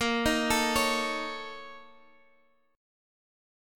A#mM7bb5 chord